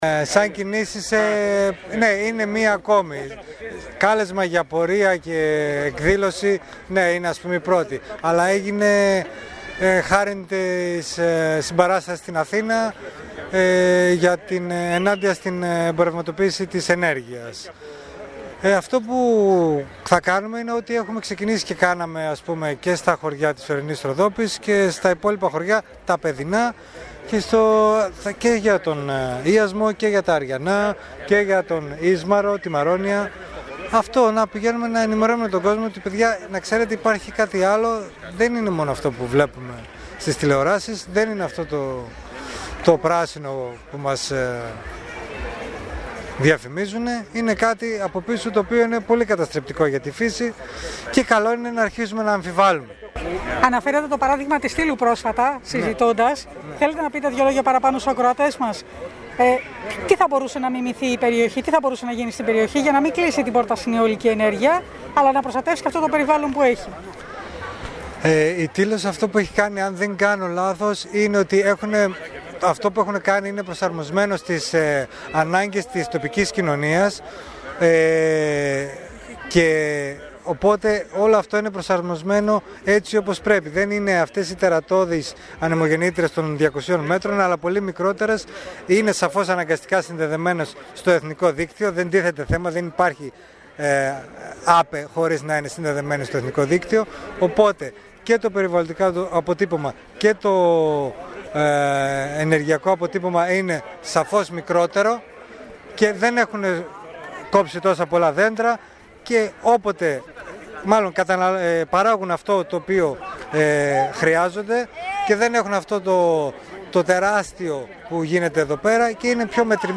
Αυτό που τόνισαν μιλώντας στην ΕΡΤ Κομοτηνής και αναφέρουν και στο ψήφισμά τους είναι να μην δεχθεί το κράτος νέες αιτήσεις για αιολικά πάρκα με την υφιστάμενη διαδικασία.